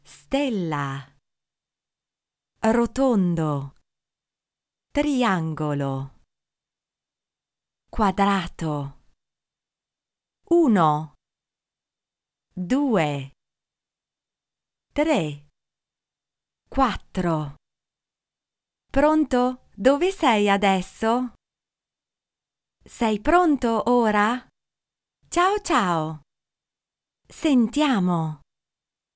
Female; 20s/30s, teenager - sunny versatile voice over - modern, fresh and lively, sexy and mellow, native Italian speaker. Standard Italian accent.
Sprecherin italienisch, Muttersprachlerin.
Sprechprobe: eLearning (Muttersprache):